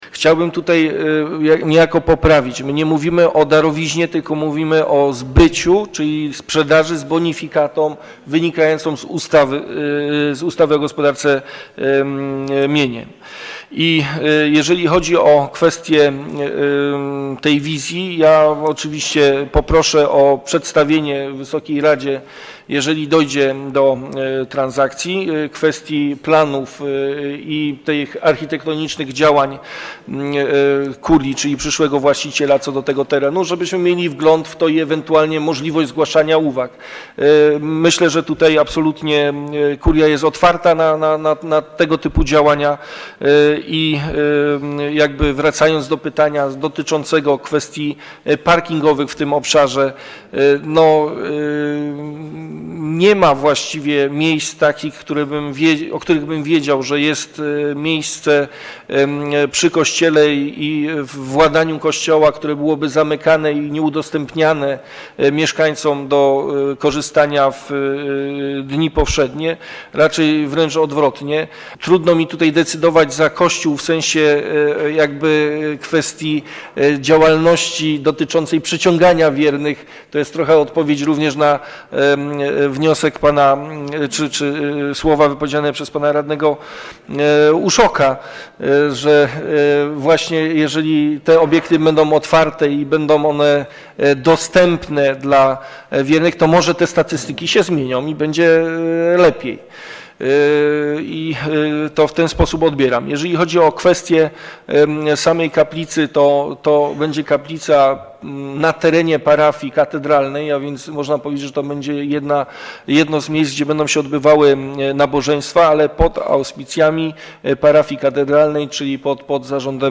Posłuchajcie co powiedział Marcin Krupa prezydent Katowic odnośnie sprzedaży działki pod kaplicę